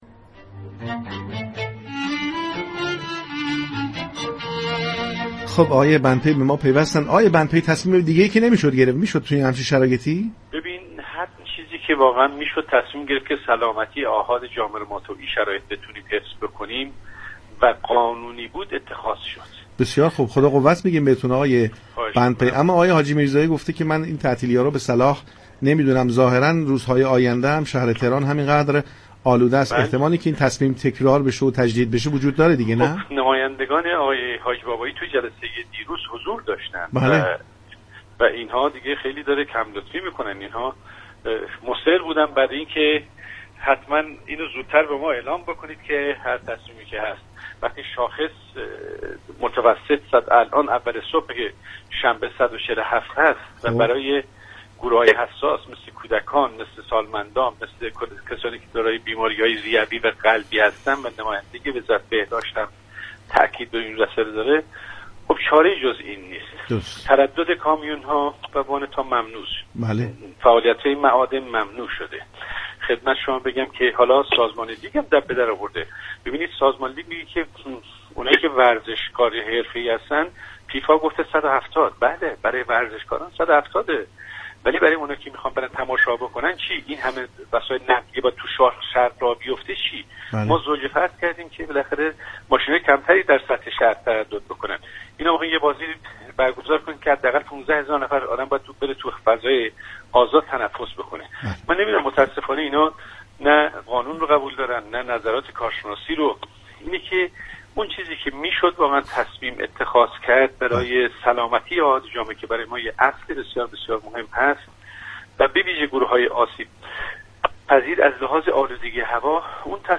انوشیروان محسنی بندپی استاندار تهران در گفتگو با پارك شهر از سازمان لیگ به دلیل اصرار در برگزاری بازی‌ها در شرایط آلودگی هوا انتقاد كرد.